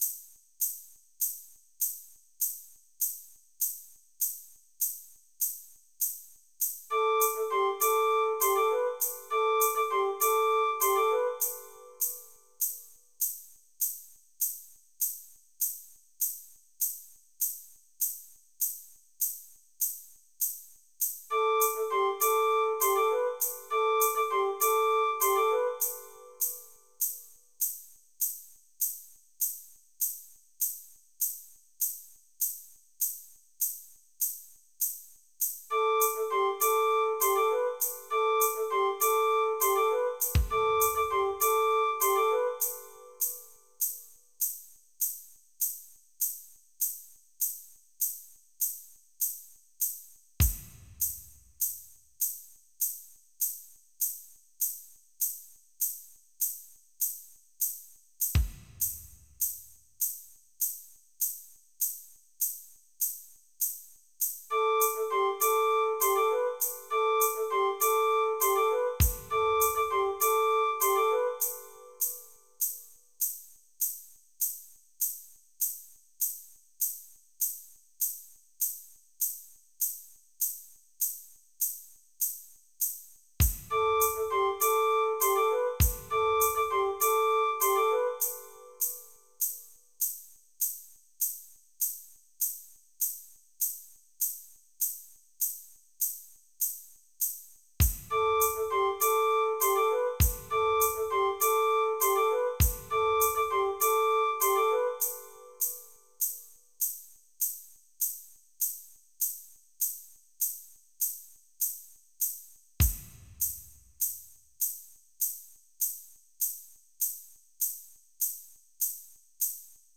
Oldies
MIDI Music File